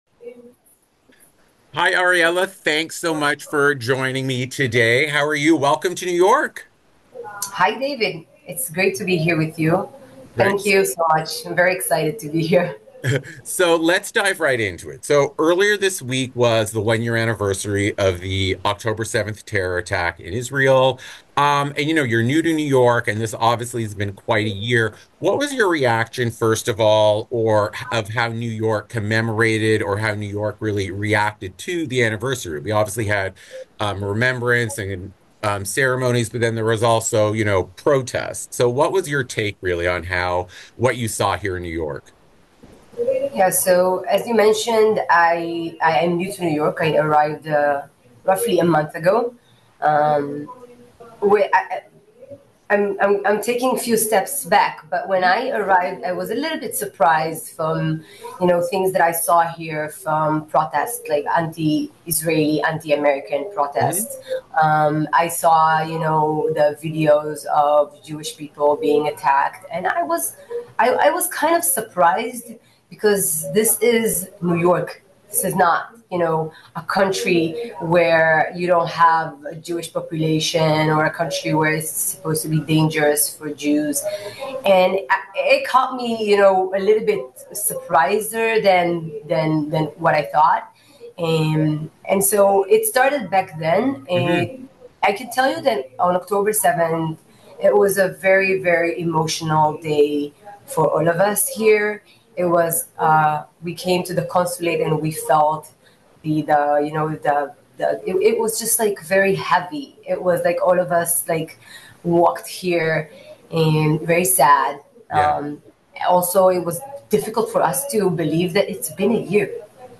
WINS Interview